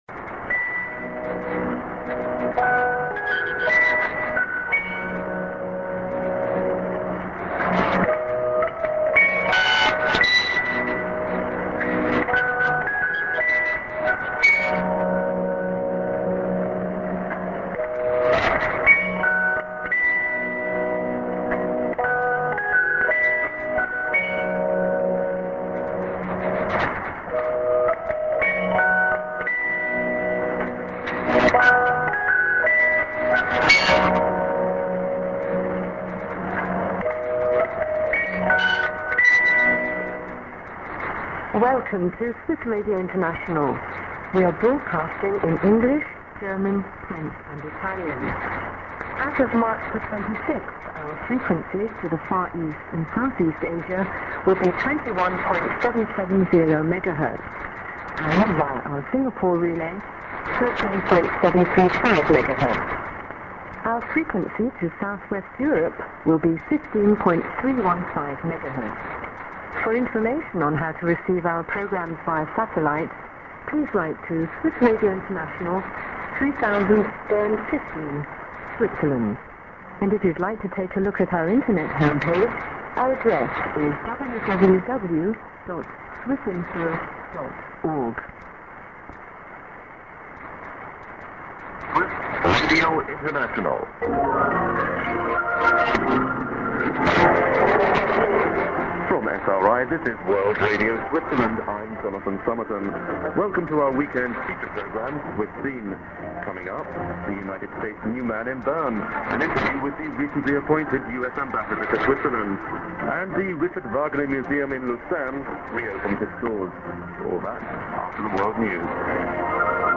ST. IS->00'40":ID+SKJ(women)->01'35":ID(man)